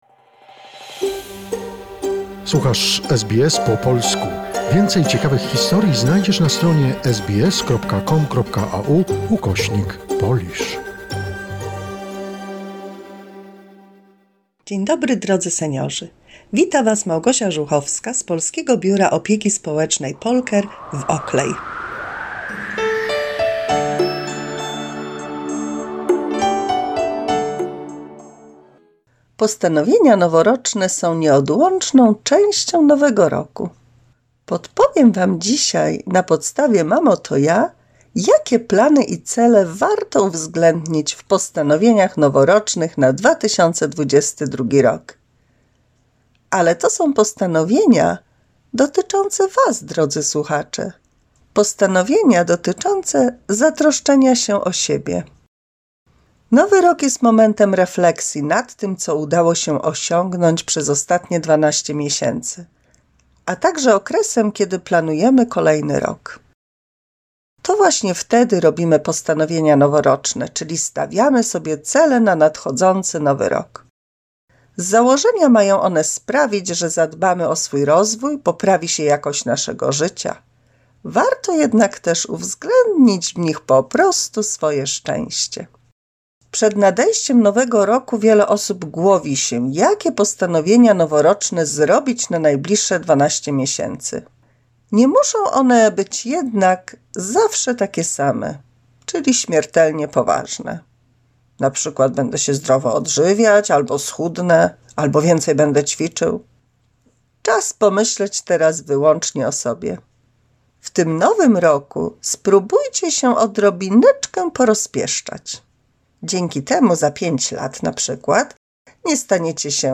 mini radio drama